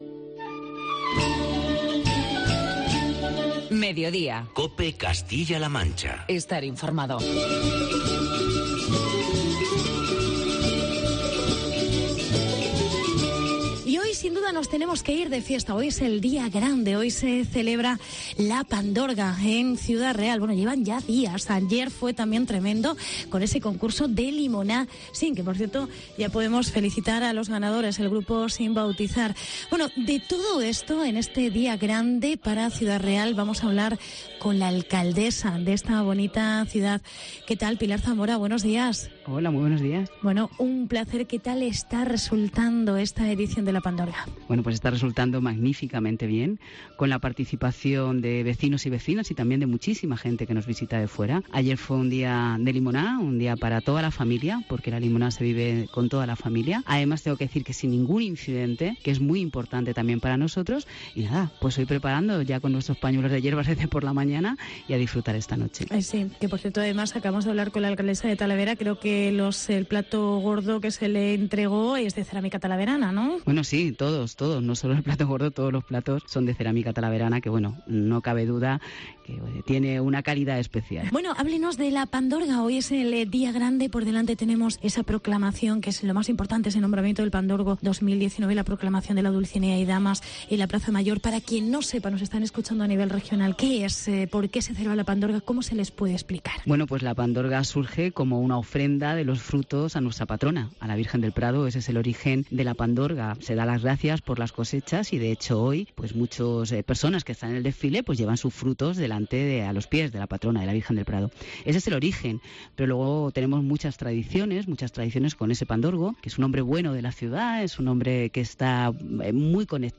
Día grande de "La Pandorga" en Ciudad Real. Entrevista con la alcaldesa Pilar Zamora